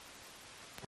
Informativo Badajoz - Jueves, 17 de diciembre